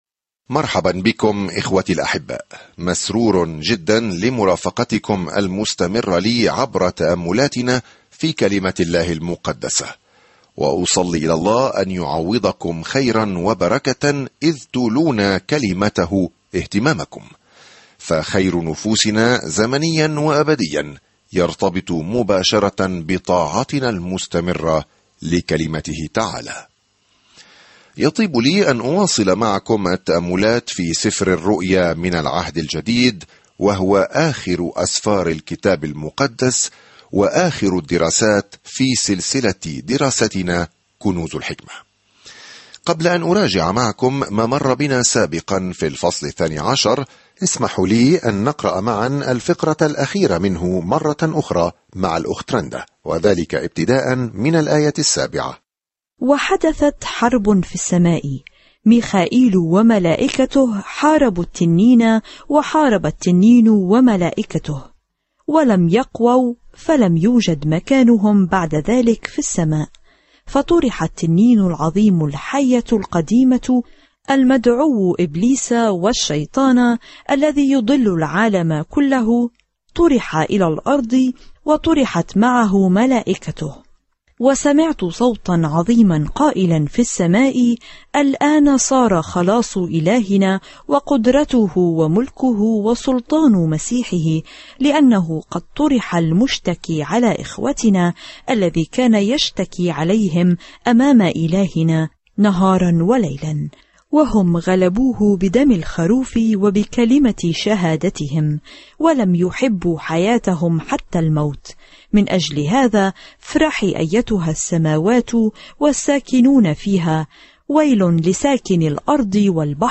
الكلمة رُؤْيَا يُوحَنَّا 1:13 يوم 39 ابدأ هذه الخطة يوم 41 عن هذه الخطة يسجل سفر الرؤيا نهاية الجدول الزمني الشامل للتاريخ مع صورة لكيفية التعامل مع الشر أخيرًا وسيملك الرب يسوع المسيح بكل سلطان وقوة وجمال ومجد. سافر يوميًا عبر سفر الرؤيا وأنت تستمع إلى الدراسة الصوتية وتقرأ آيات مختارة من كلمة الله.